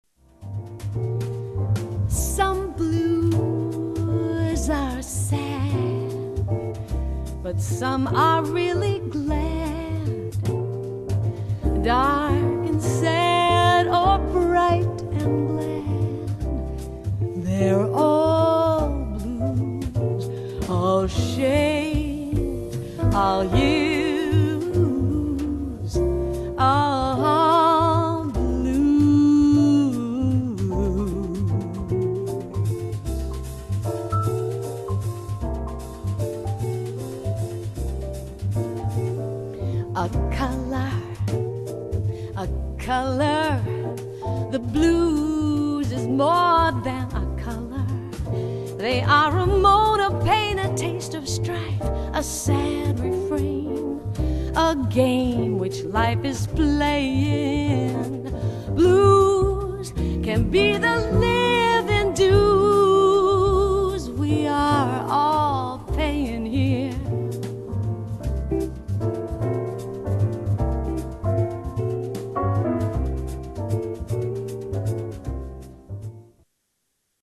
voce
chitarra
pianoforte
contrabbasso
batteria
at Acoustic Recording Studio, Brooklin, N.Y.C.